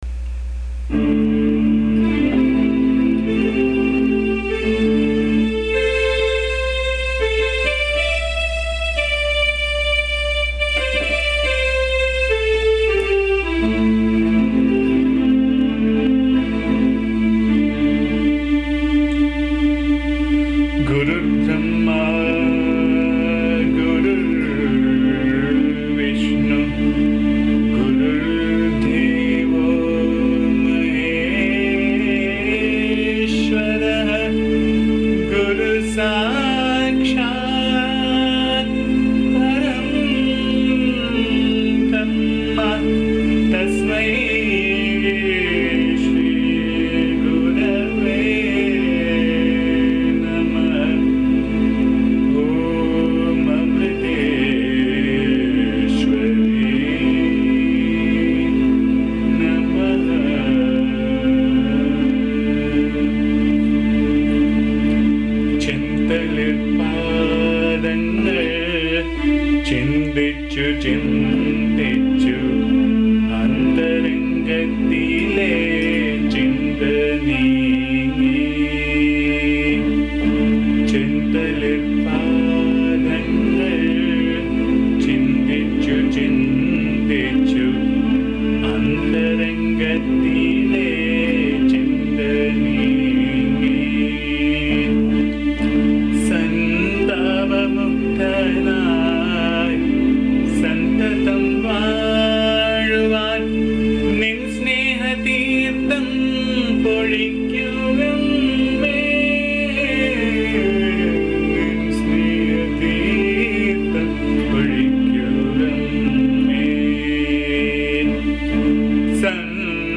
This is set in the raga Madhyamaavathi.
AMMA's bhajan song